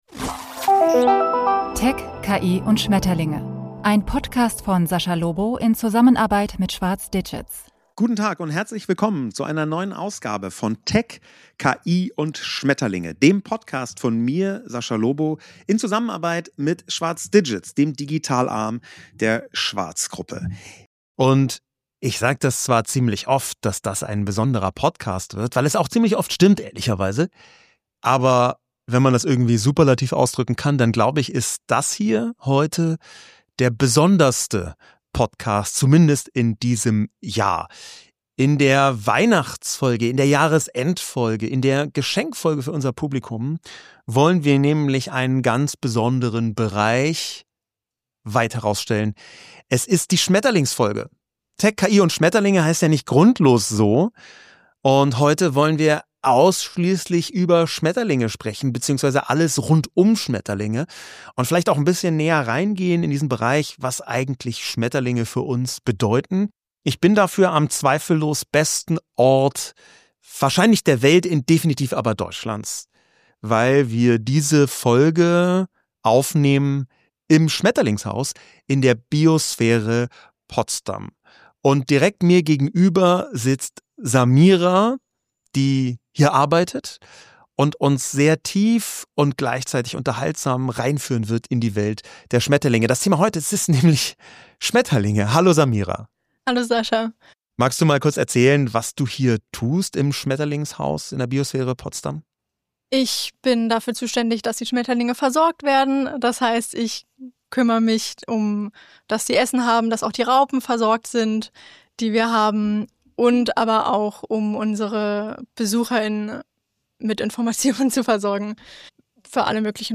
Beschreibung vor 3 Monaten In der besonderen Weihnachtsfolge von Tech, KI und Schmetterlinge dreht sich alles um die namensgebenden Schmetterlinge. Aufgenommen im Schmetterlingshaus der Biosphäre Potsdam